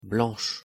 Правильное произношение
Байредо Парфюмс Бланш о дю парфам
fr_blanche.mp3